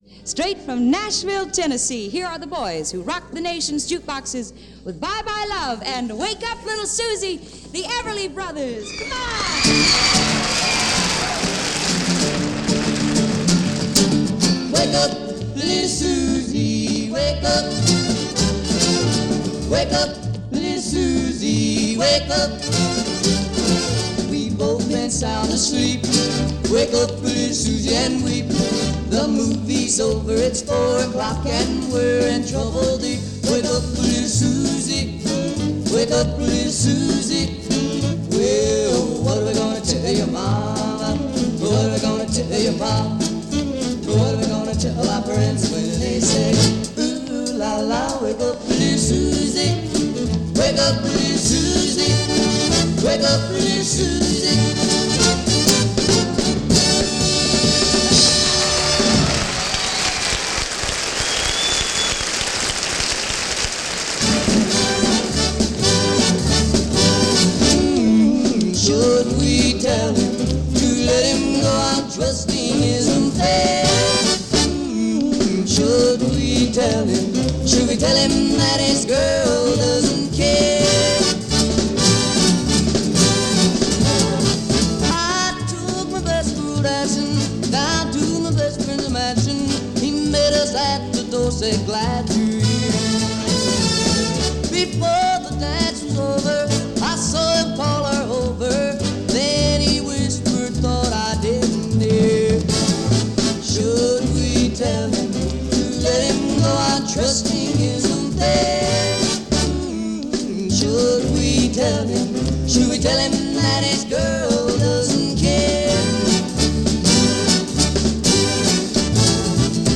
Live TV, nothing like it.